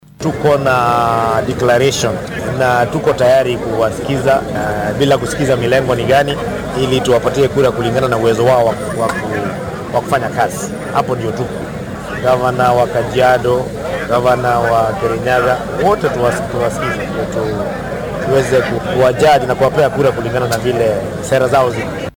Waxaa arrintan ka hadlay guddoomiyaha ismaamulka Tharaka Nithi Onesmus Muthomi Njuki